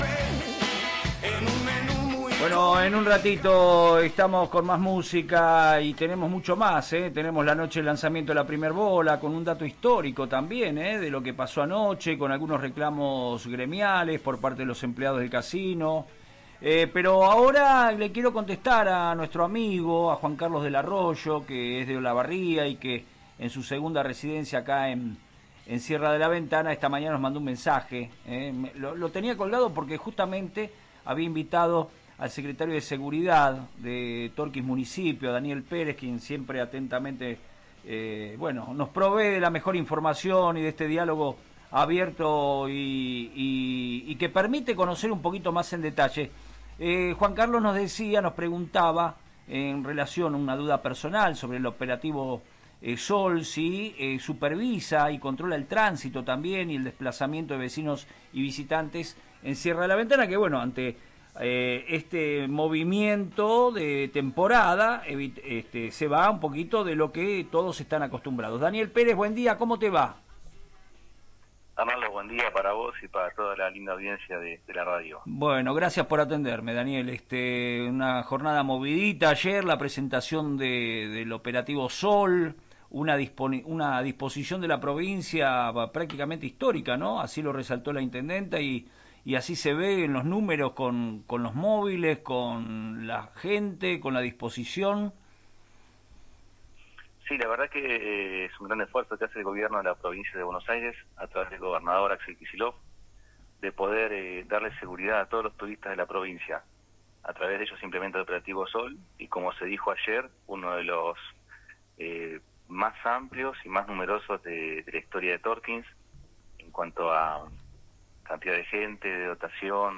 El Secretario de Turismo, Daniel Perez, destacó en FM Reflejos el arribo de nuevos agentes y móviles al distrito para la temporada estival. Pese a los recortes de fondos nacionales, el municipio apuesta a un esquema que prioriza la educación vial.